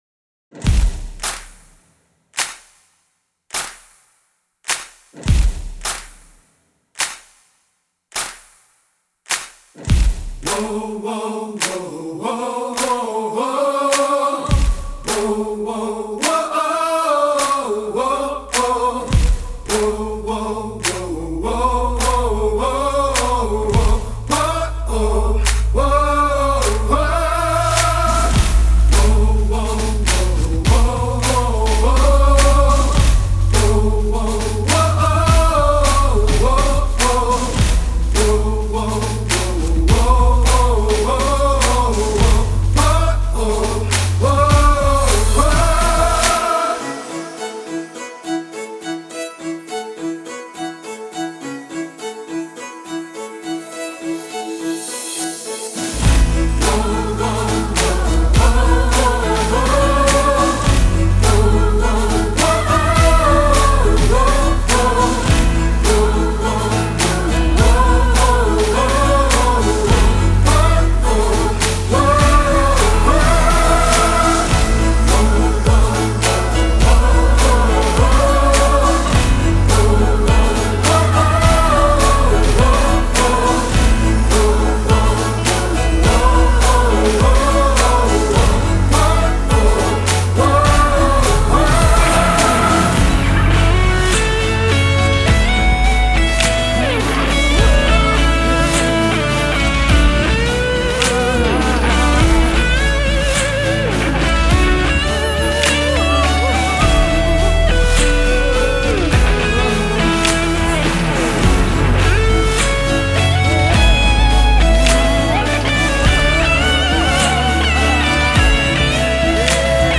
「チカラ」と「勇気」を与えるサウンド